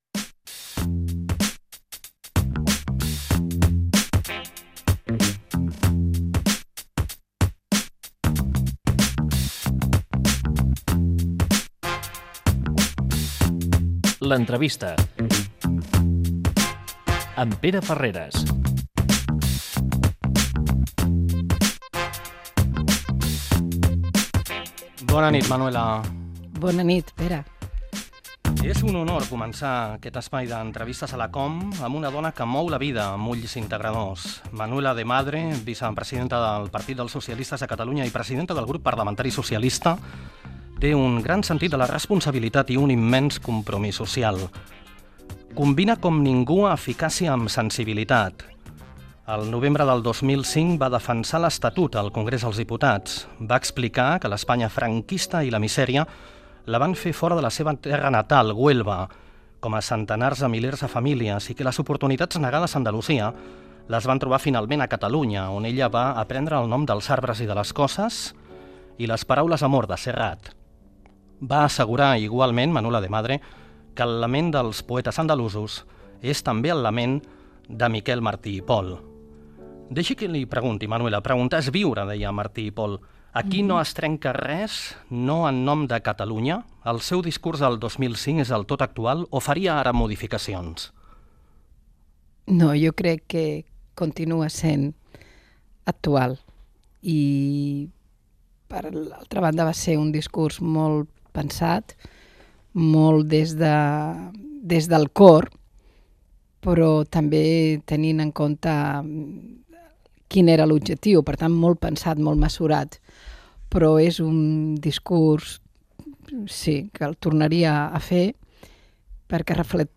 L'entrevista
Careta del programa i presentació del primer programa amb una entrevista a la política del Partit Socialista de Catalunya Manuela de Madre.